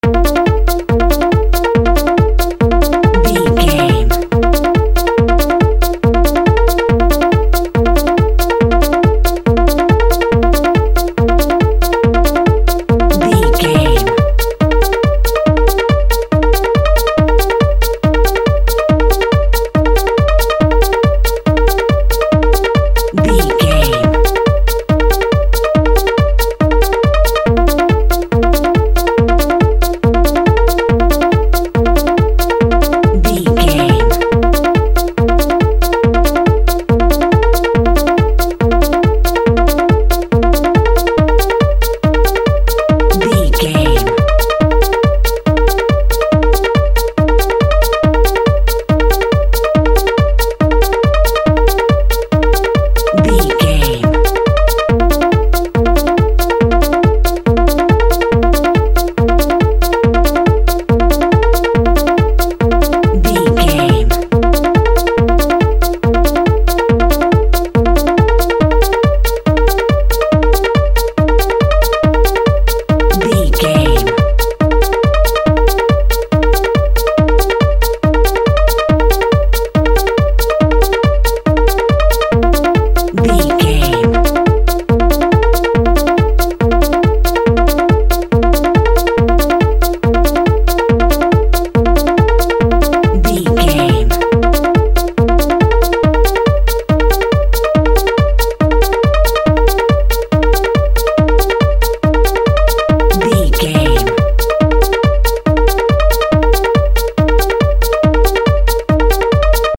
The Sound of Trance Music.
Ionian/Major
B♭
Fast
energetic
uplifting
hypnotic
electronic
synth lead
synth bass
Electronic drums
Synth pads